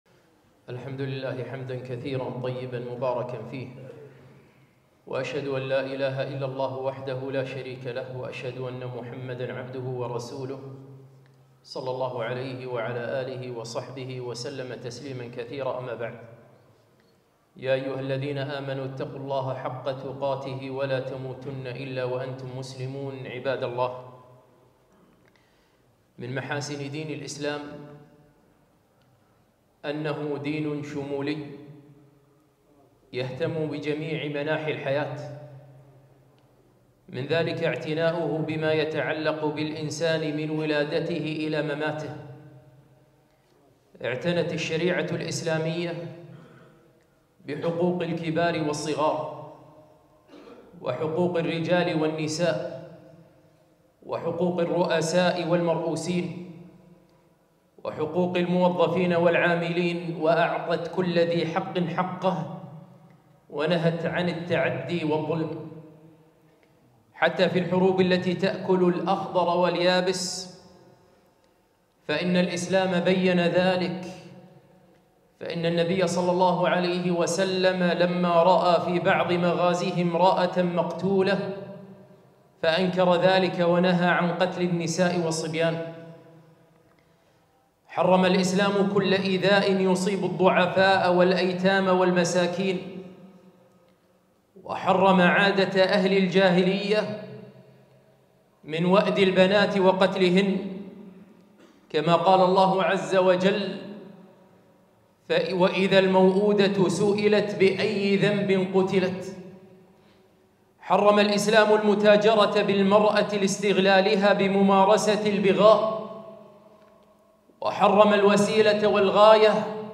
خطبة - هل في قوانين البشرية حفظ لحقوق الإنسان مثل الإسلام؟